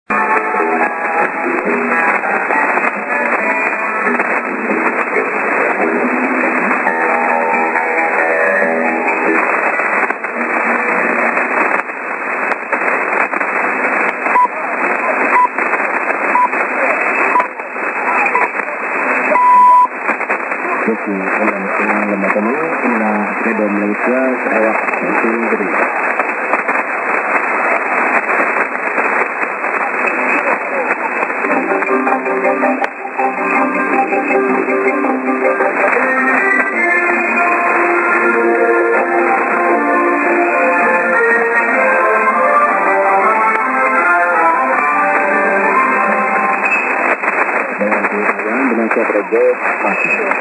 music->TS->ID(man)->